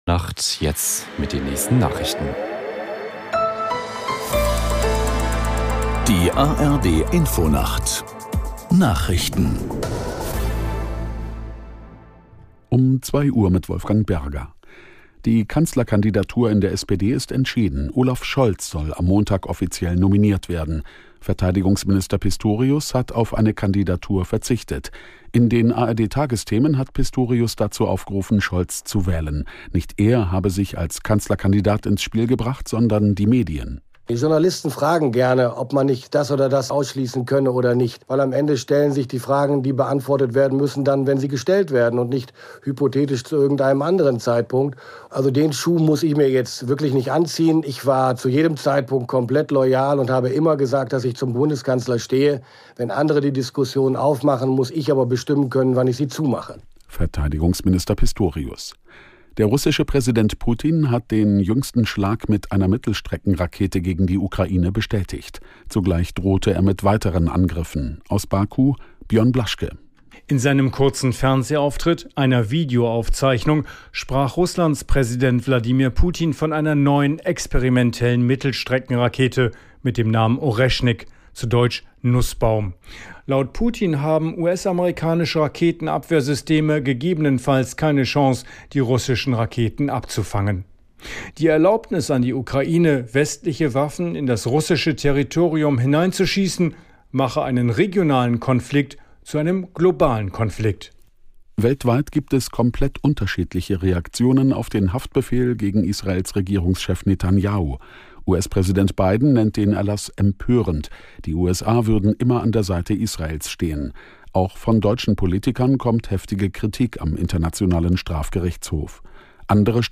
1 Nachrichten 4:50